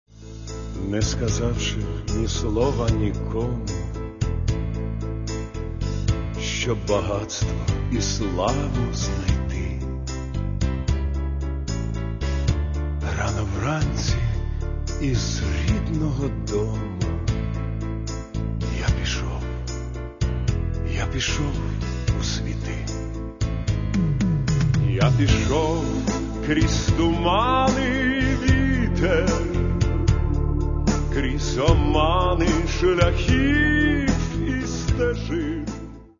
Здається, цим пісням дещо бракує тихого шурхотіння.